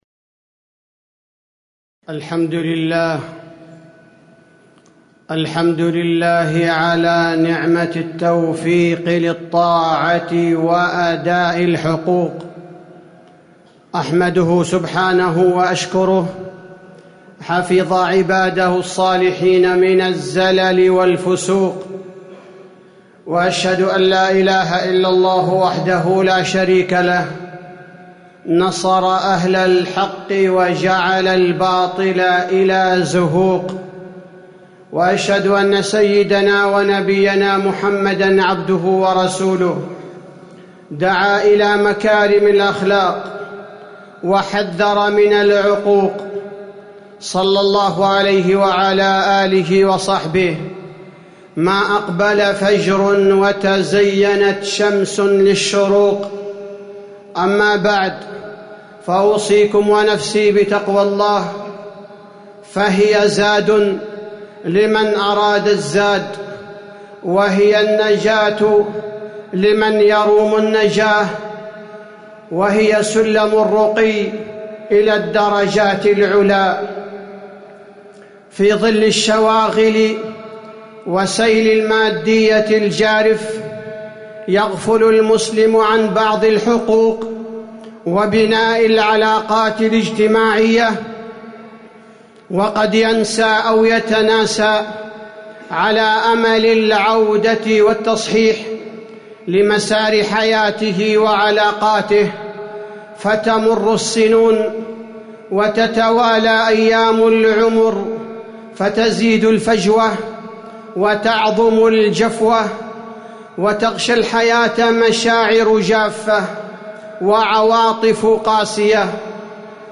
تاريخ النشر ٢٢ ربيع الأول ١٤٤٠ هـ المكان: المسجد النبوي الشيخ: فضيلة الشيخ عبدالباري الثبيتي فضيلة الشيخ عبدالباري الثبيتي حق المسلم على المسلم